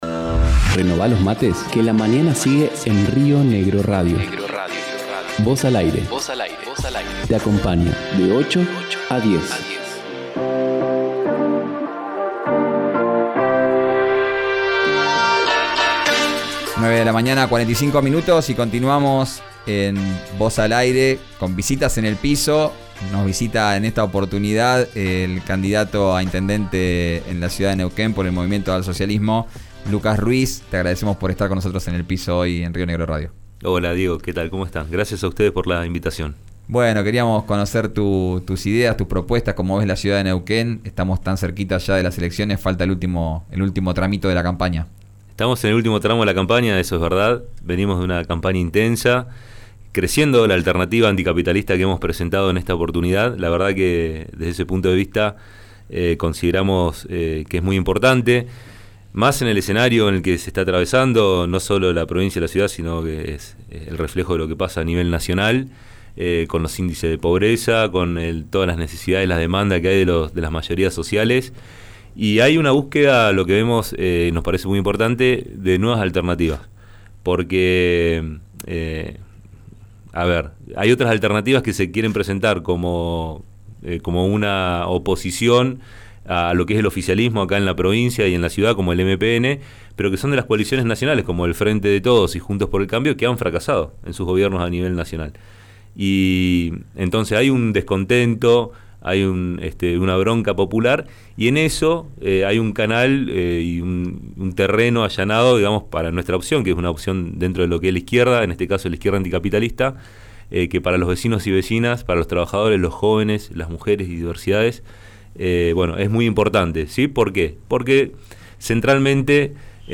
visitó el estudio de RÍO NEGRO RADIO. Escuchá la entrevista completa en 'Vos al Aire'.